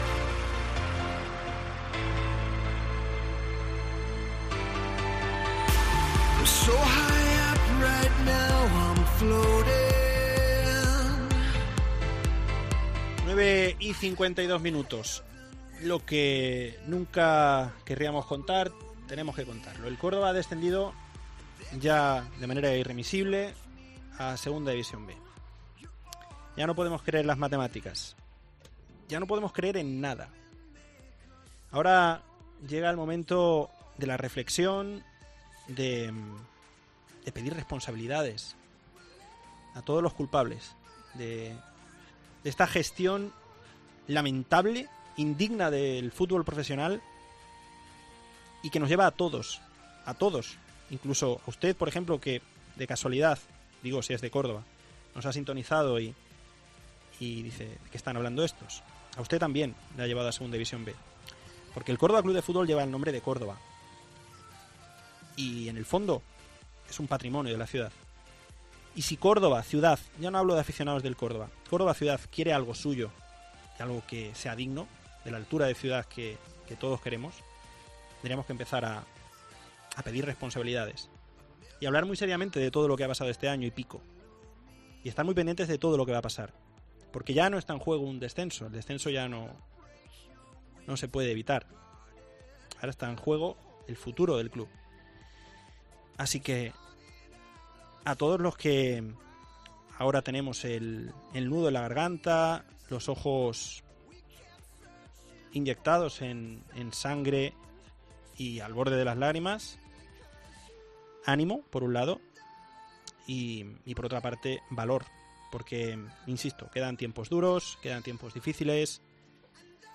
resumen sonoro las palmas ccf